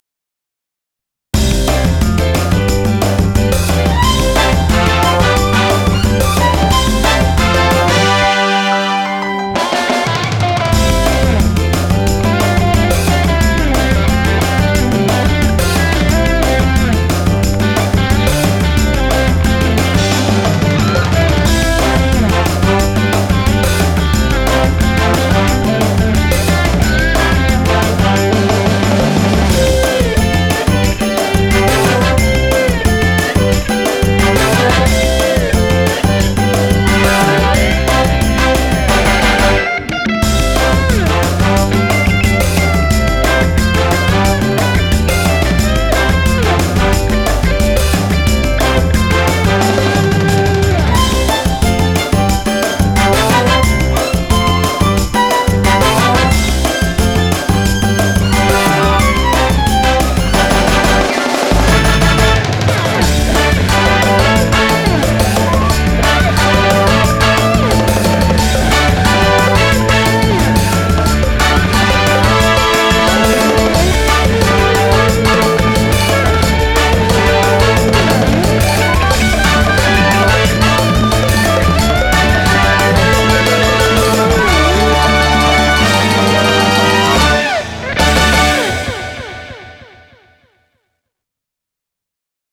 MEXICAN FOLK SONG
BPM179
Audio QualityLine Out